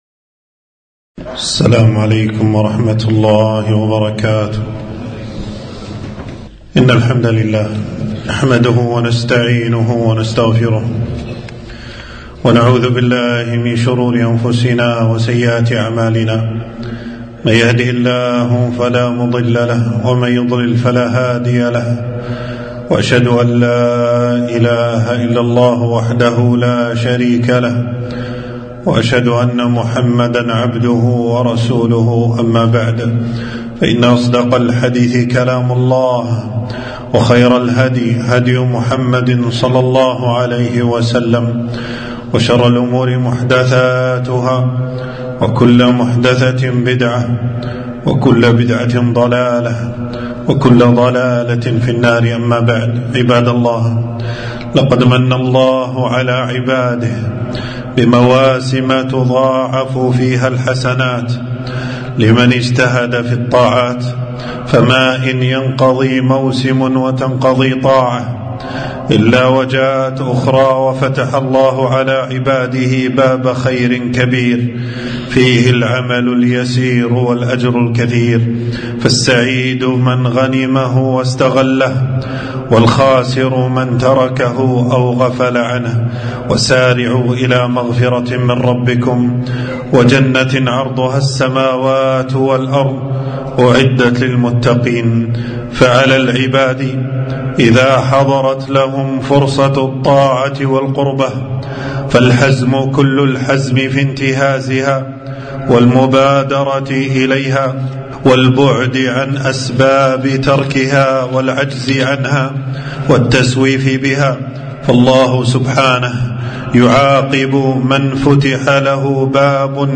خطبة - شعبان شهر الصيام والقرآن